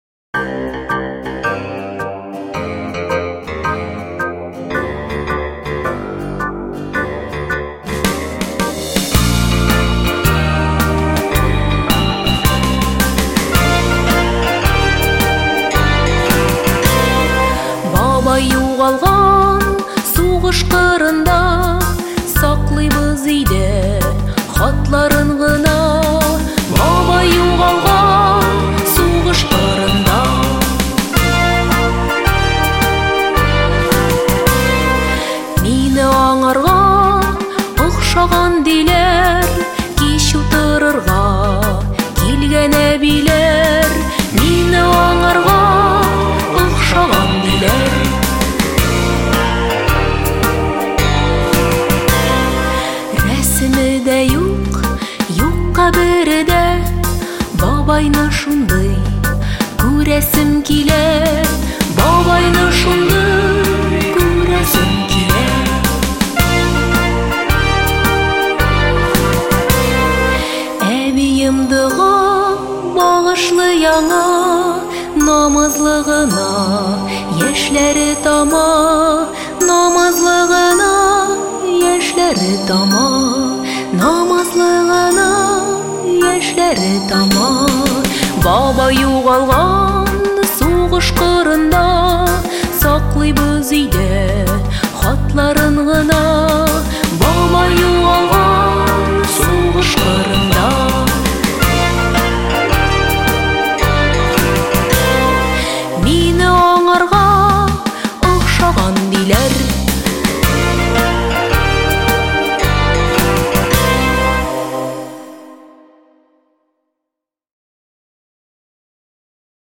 • Категория: Детские песни
татарские детские песни